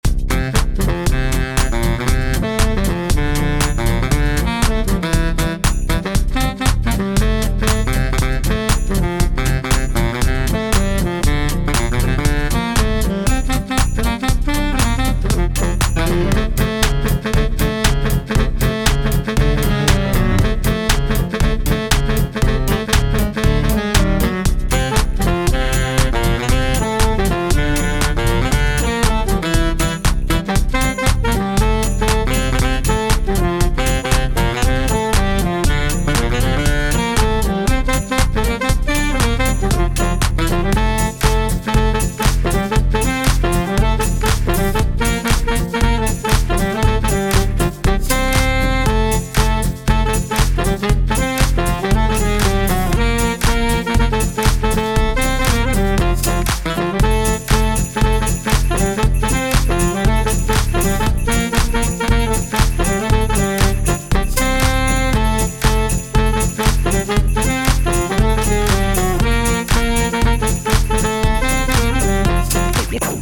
クリスマス楽曲として制作したエレクトロスウィング楽曲。